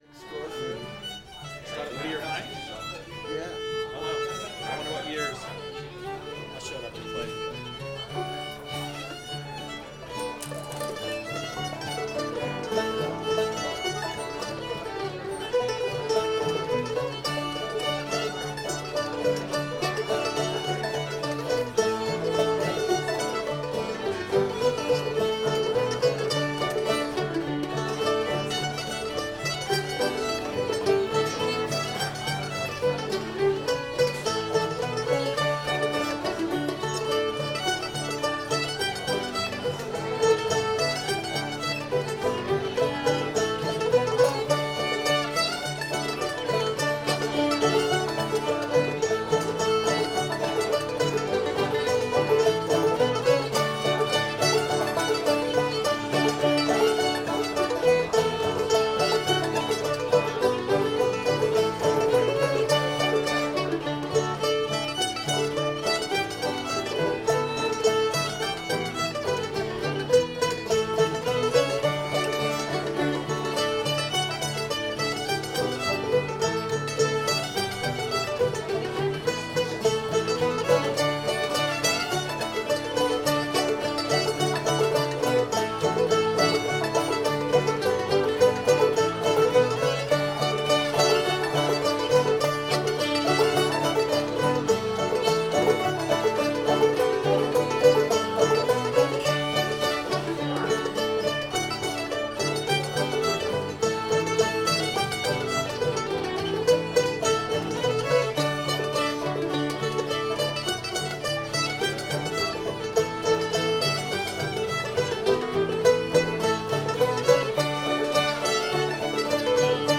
katy bar the door [D]